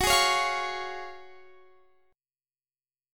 F#M7sus2 Chord
Listen to F#M7sus2 strummed